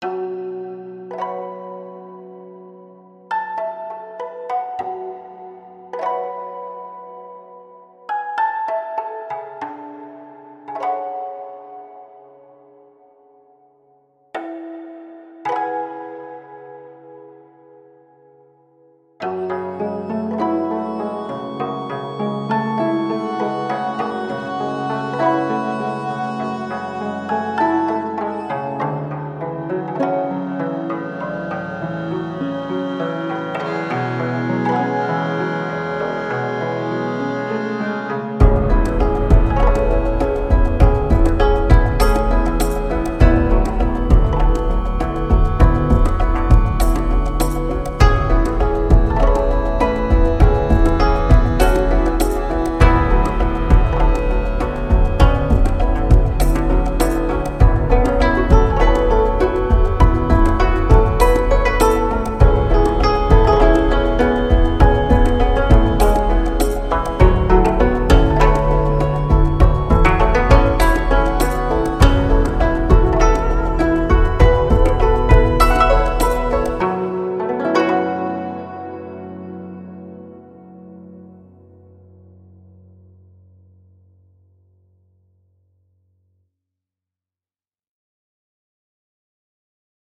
复杂而详细的Kontakt样本库，具有中国古筝的真实而优美的音色！
指弹拨和拾音技术
谐波和弯曲（整个/半音）
古筝也被称为中国古筝，是一种古老的乐器，由21根弦组成，伸展在装饰性的木制长琴身上，并带有可调节的琴桥。